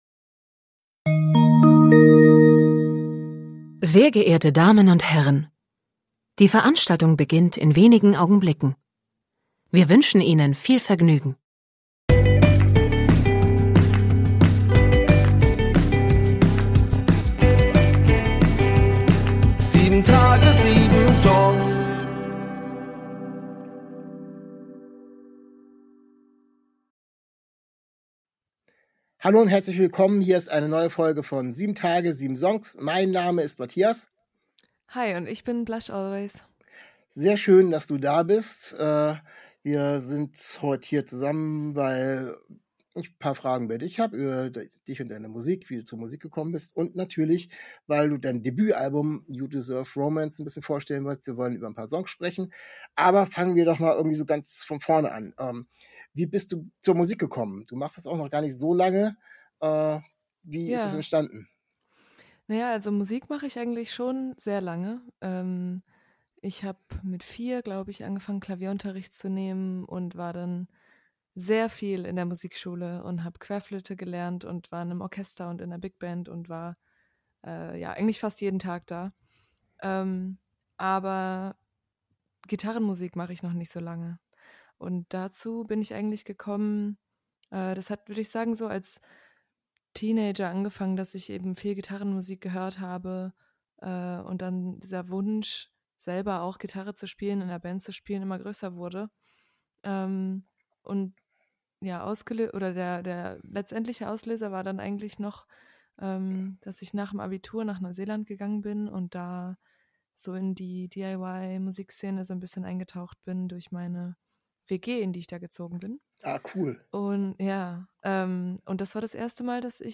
Interviewpartnerin